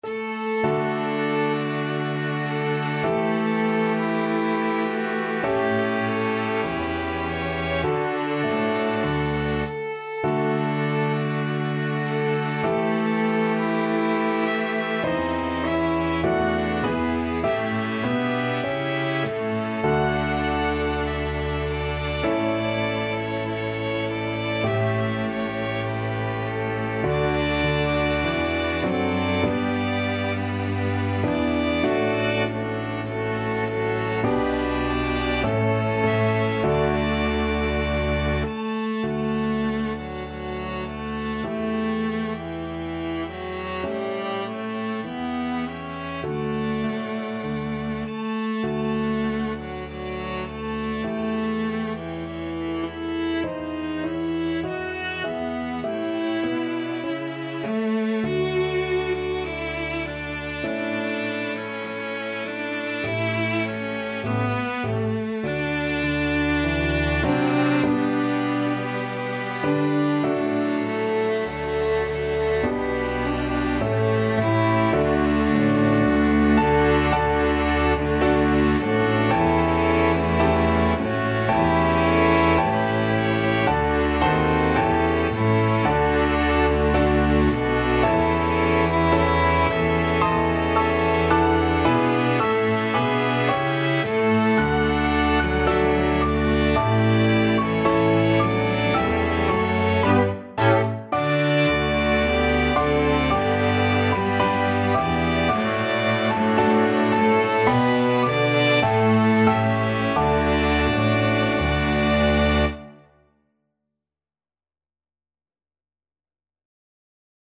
Free Sheet music for Piano and Ensemble
Violin 1Violin 2ViolaCelloDouble BassPiano
Risoluto = c.100
D major (Sounding Pitch) (View more D major Music for Piano and Ensemble )
4/4 (View more 4/4 Music)
Piano and Ensemble  (View more Intermediate Piano and Ensemble Music)
Traditional (View more Traditional Piano and Ensemble Music)